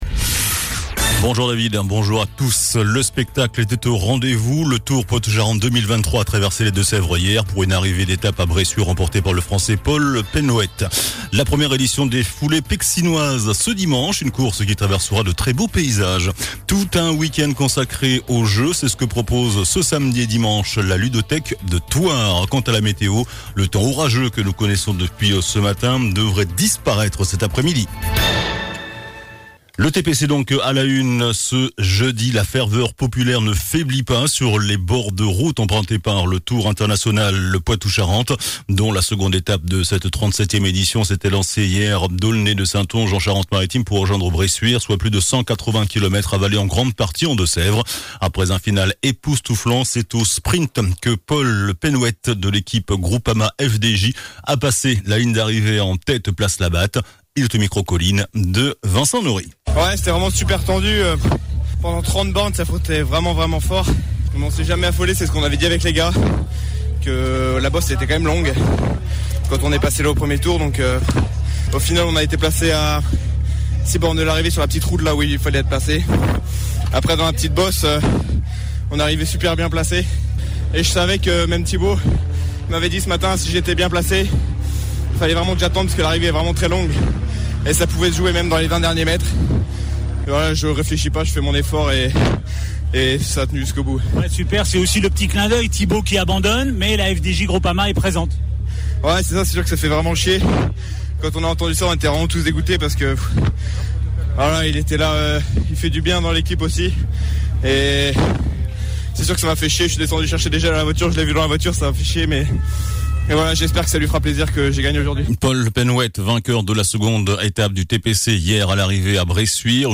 JOURNAL DU JEUDI 24 AOÛT ( MIDI )